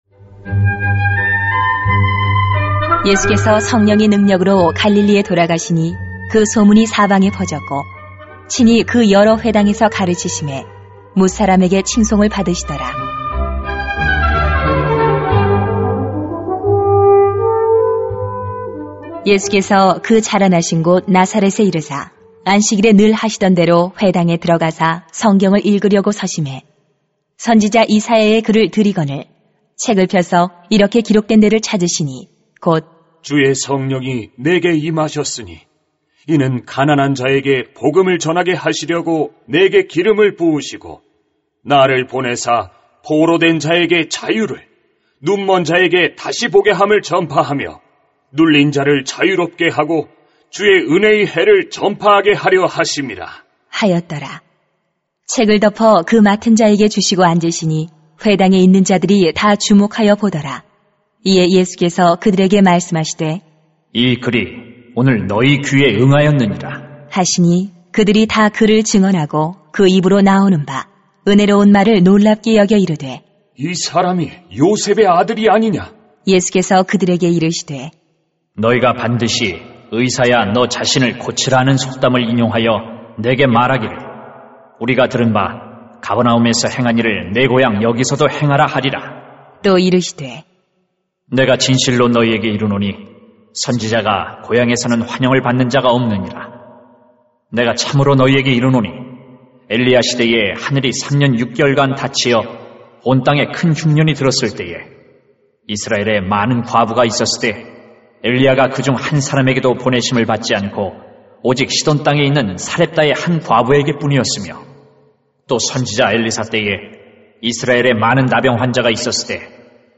[눅 4:14-30] 성령의 능력으로 > 새벽기도회 | 전주제자교회